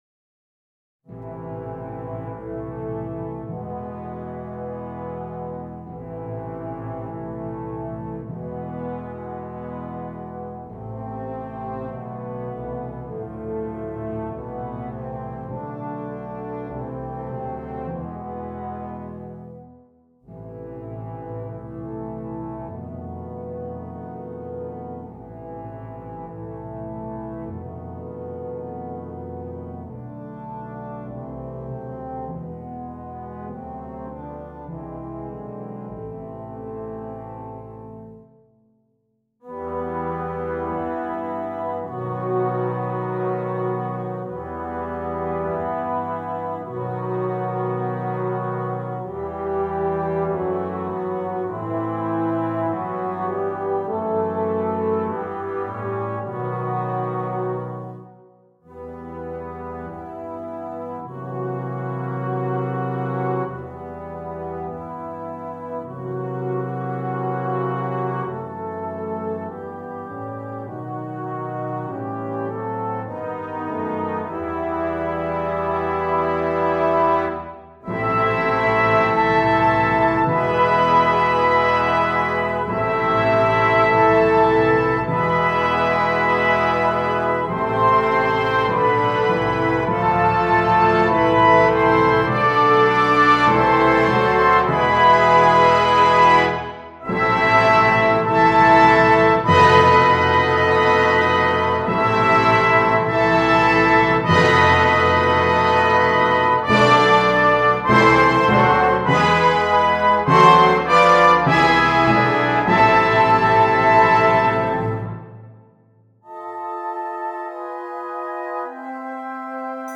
Brass Choir